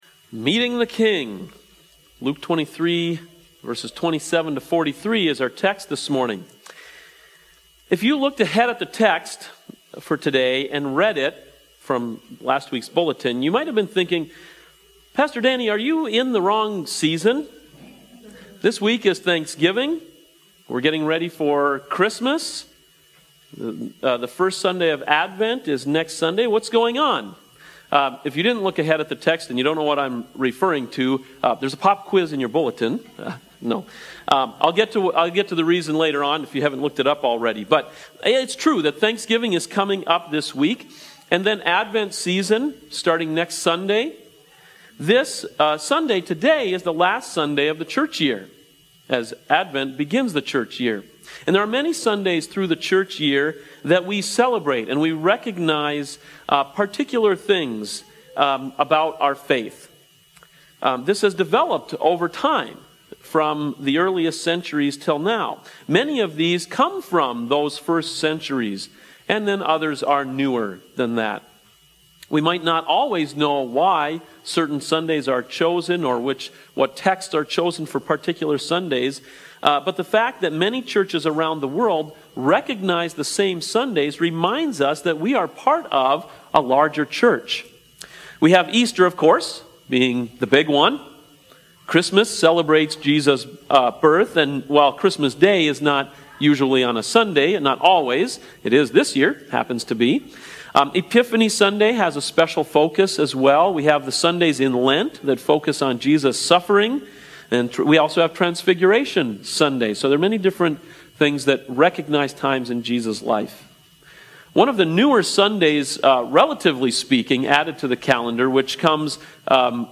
Sermons Meeting the King (Luke 23:27-43)